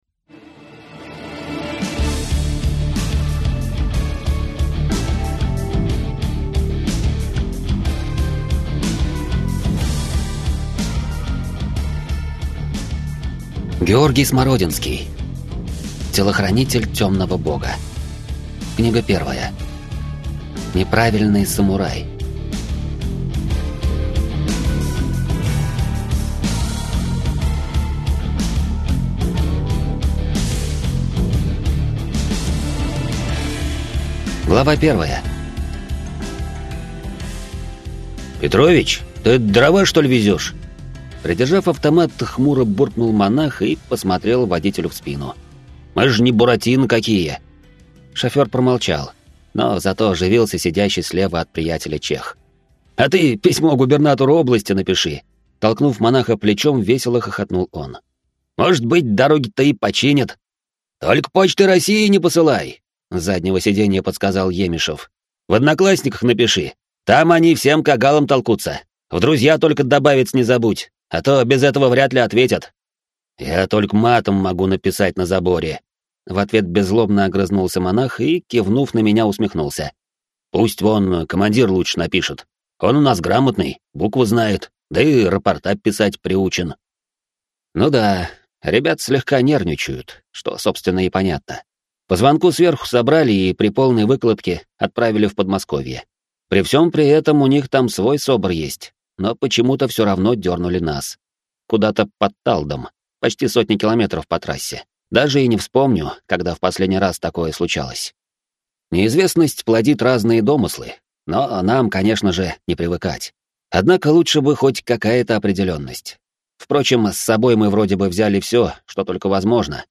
Аудиокнига Неправильный самурай | Библиотека аудиокниг